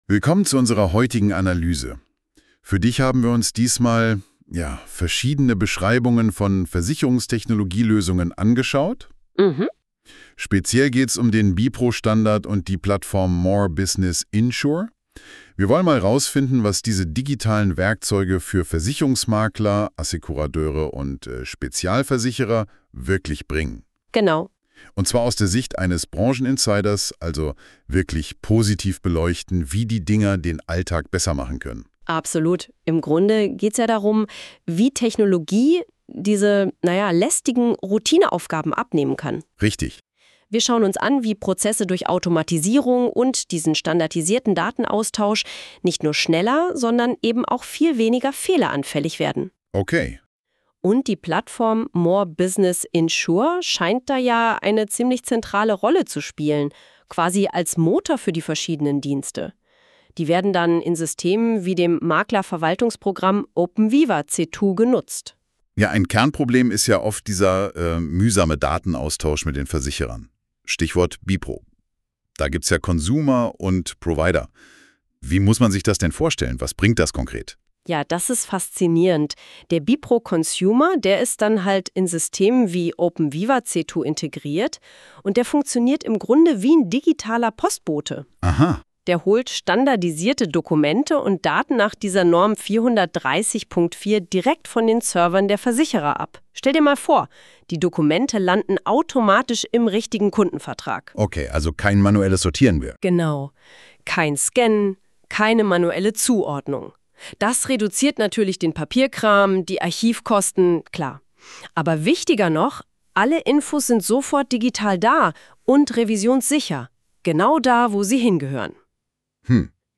Modulgruppen moreBusiness Play Episode Pause Episode Mute/Unmute Episode Rewind 10 Seconds 1x Fast Forward 30 seconds 00:00 / 00:06:06 In neuem Fenster abspielen | Audiolänge: 00:06:06 moreBusiness Was kann mir eine Standardsoftware für die Versicherungsbranche bieten? Praxisnah und verständlich erklärt uns die künstliche Intelligenz von Google NotebookLM die Modulgruppen von openVIVA c2.